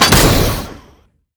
JackHammer_1p_01.wav